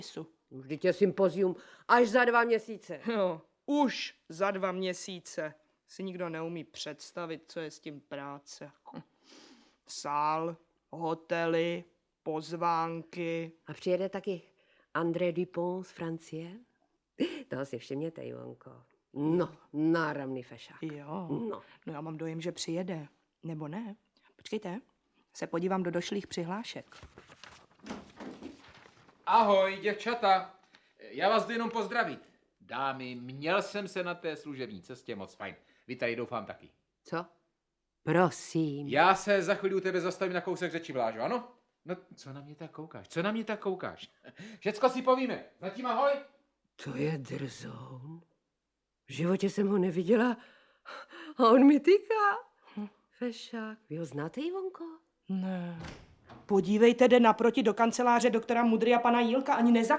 Audiobook
Read: Vlastimil Brodský